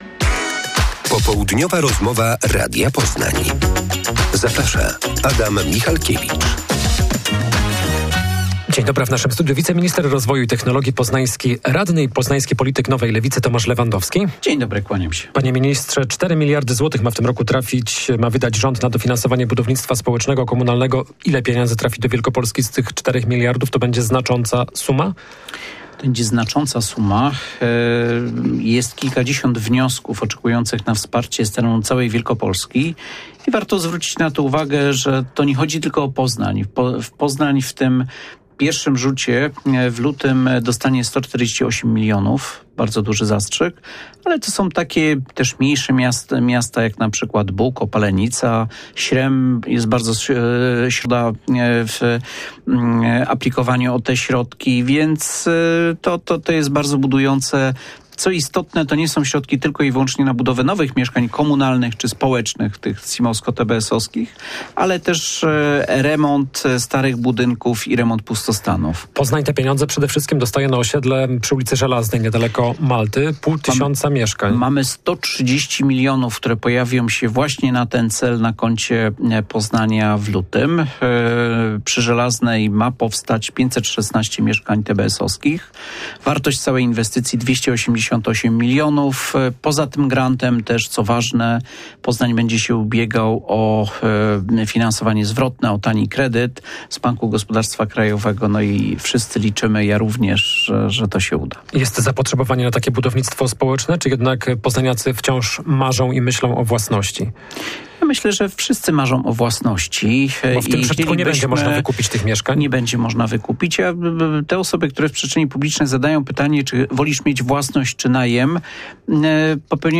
Rozmowa z wiceministrem rozwoju Tomaszem Lewandowskim z Nowej Lewicy.